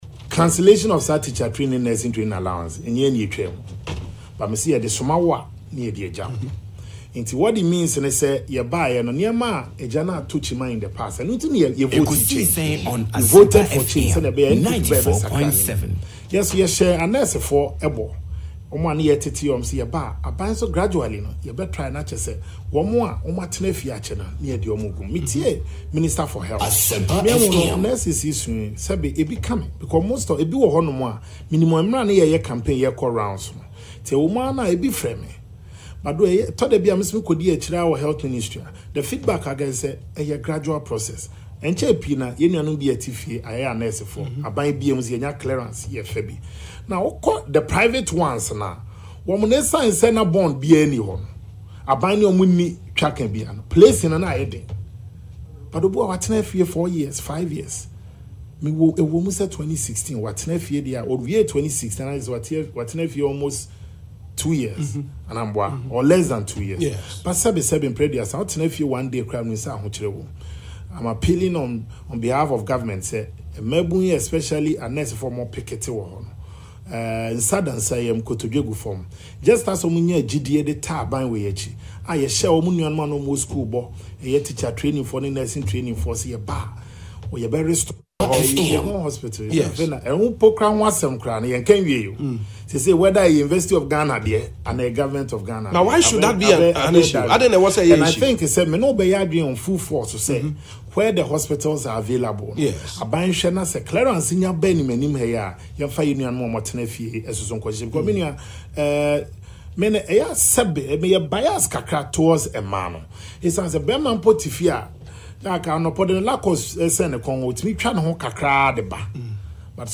Sammi Awuku was speaking on Asempa FM’s late afternoon programme, Ekosii Sen Tuesday.